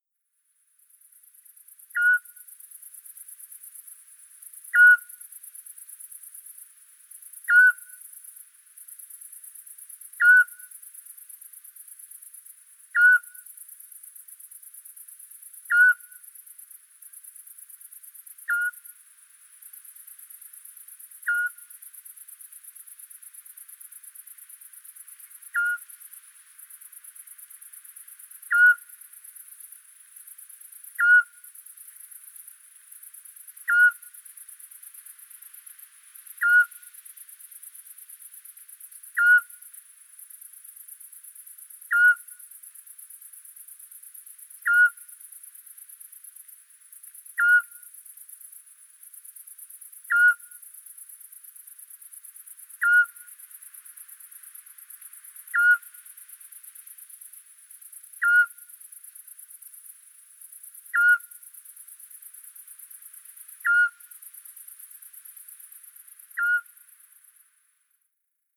Le Hibou Petit-duc scops est un petit rapace nocturne de la taille d’un poing et qui possède un chant sifflant bref qui se répète toutes les 2 à 3 secondes de type « kiou ». On peut l’entendre à une longue distance c’est-à-dire environ 1 km.
Chant du Hibou petit-duc scops